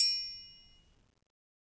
Triangle6-Hit_v1_rr1_Sum.wav